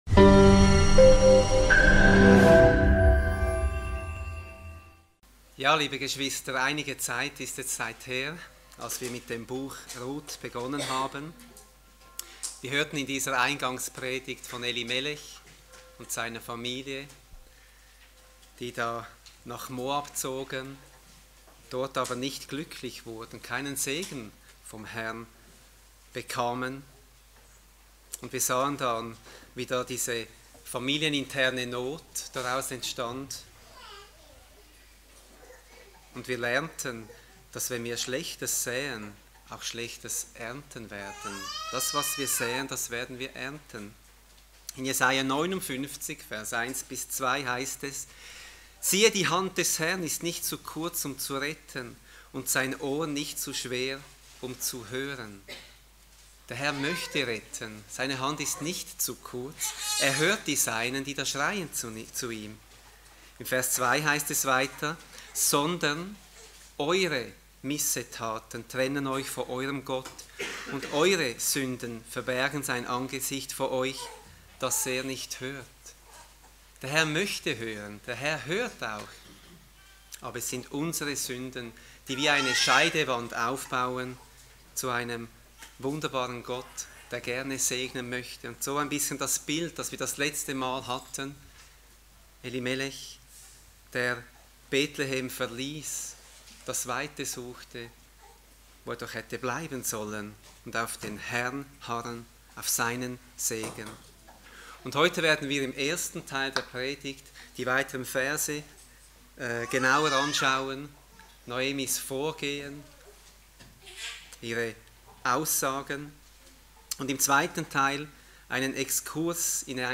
Eine predigt aus der serie "Ruth."